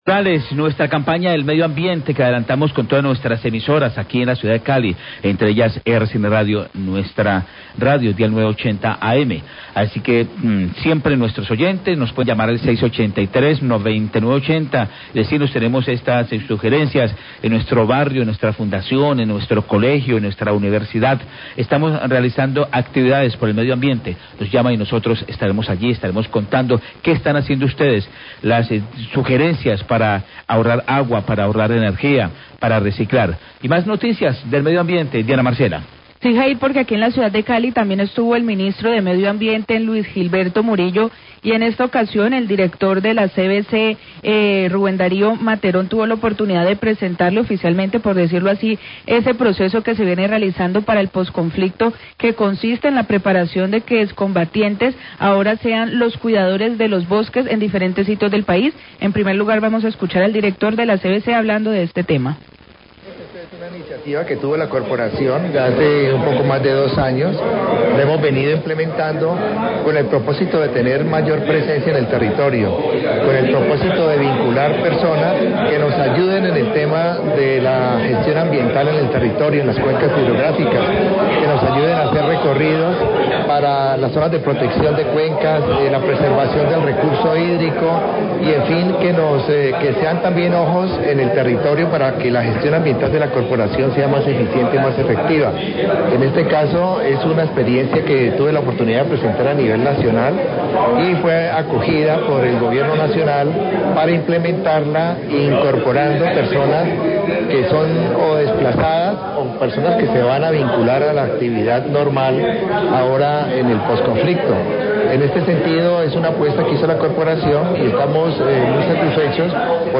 Radio
El director de la CVC, Rubén Darío Materón, habla sobre el programa de postconflicto, con el que se busca que desmovilizados se conviertan en guardabosques.